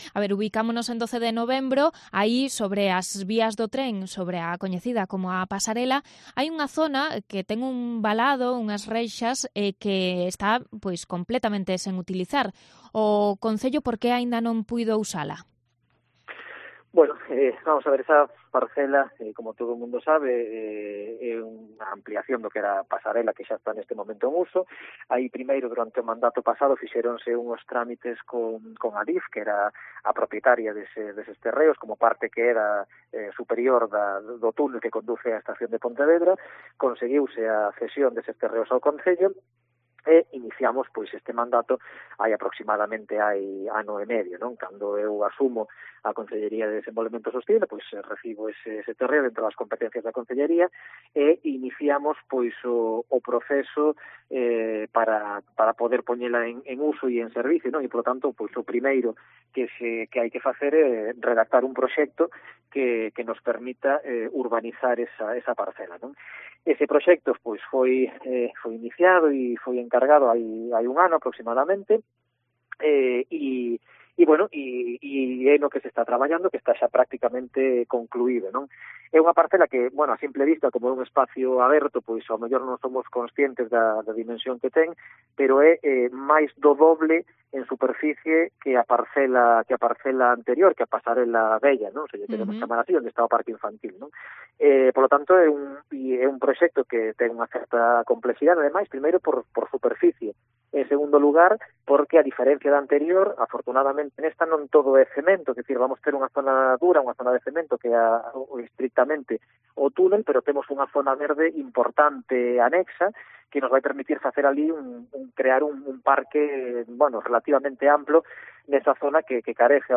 Entrevista al concejal de Desenvolvemento Sostible de Pontevedra, Iván Puentes